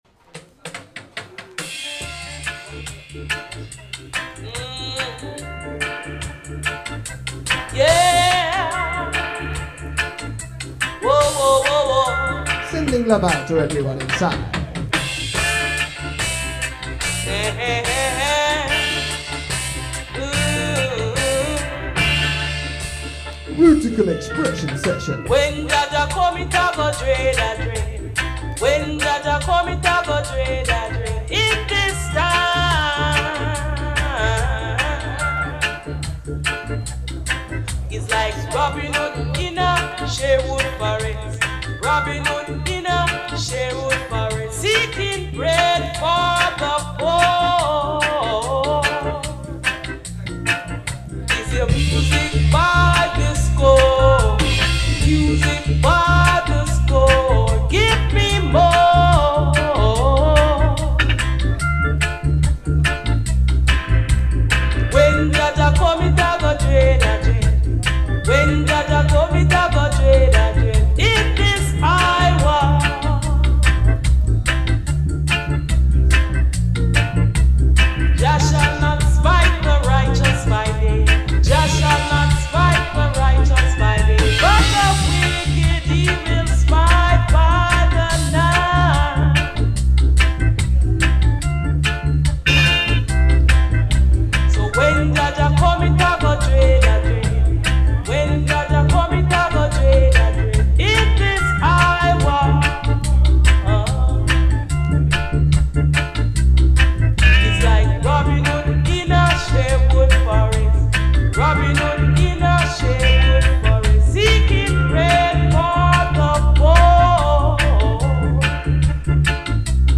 bringing some more drum and melodica galore alongside the selection.